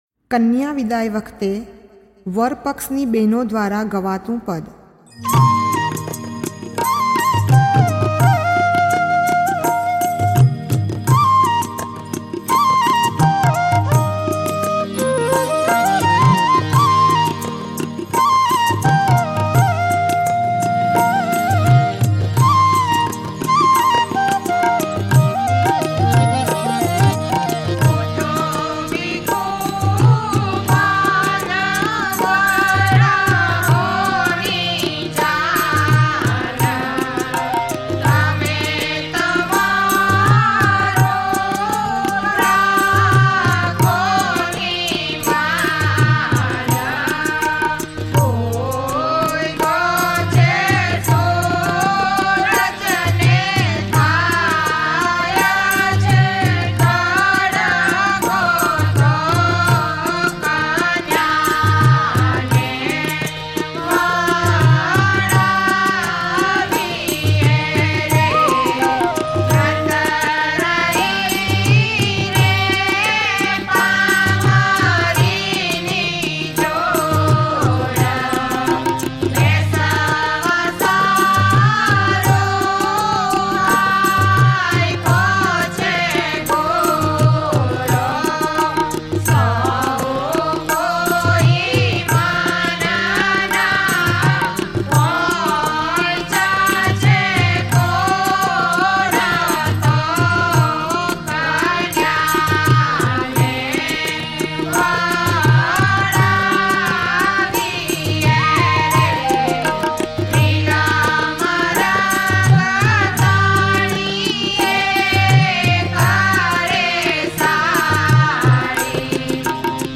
કન્યા વિદાય વખતે વર પક્ષની બેનો દ્વારા ગવાતું પદ ...નાદબ્રહ્મ ...